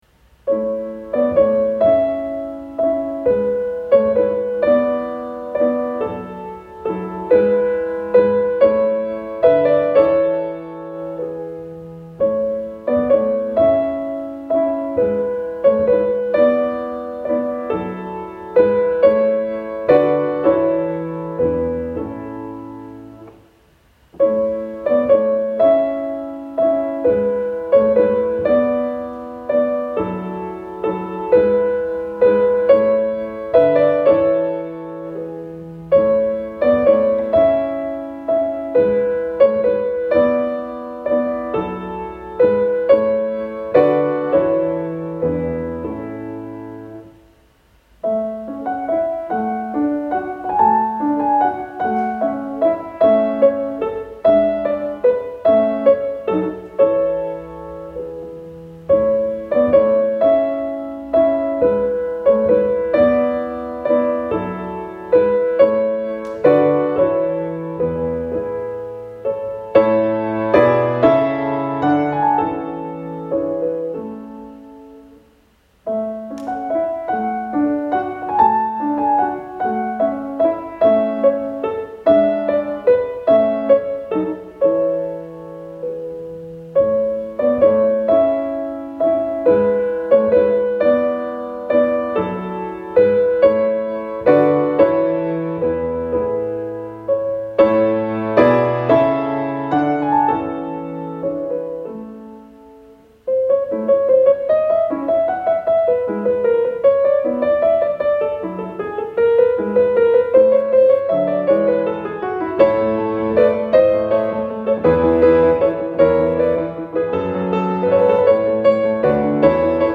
At my Master’s lecture recital held on March 30, 2024, I presented and performed three piano compositions spanning different stylistic periods.